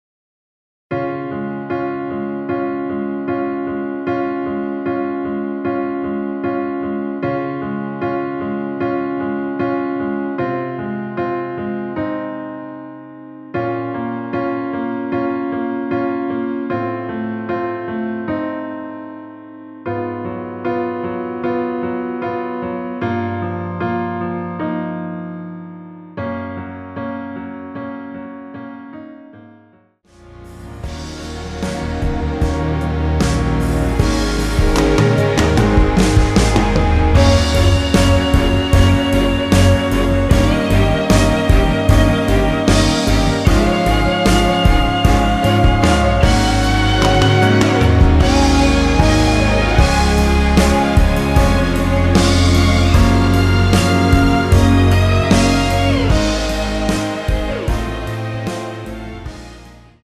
엔딩이 페이드 아웃이라 라이브 하시기 좋게 엔딩을 만들어 놓았습니다.(원키 미리듣기 참조)
Db
앞부분30초, 뒷부분30초씩 편집해서 올려 드리고 있습니다.
중간에 음이 끈어지고 다시 나오는 이유는